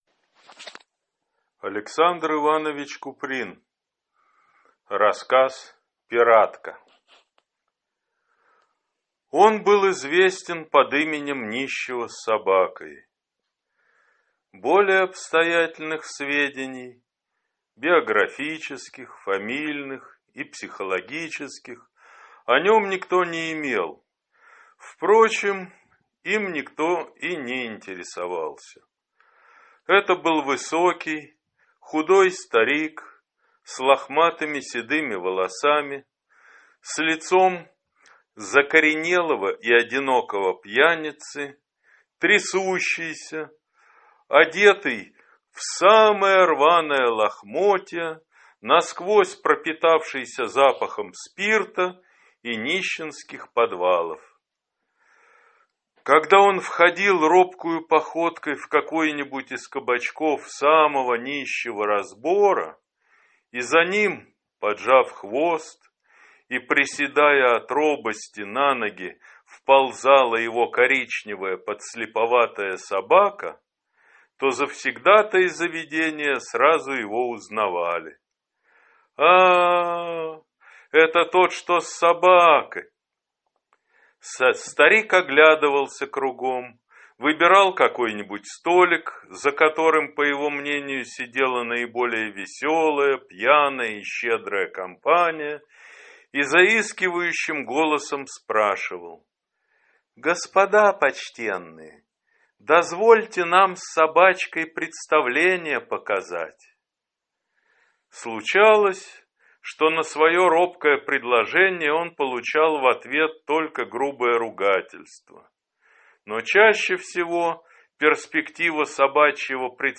Аудиокнига Пиратка | Библиотека аудиокниг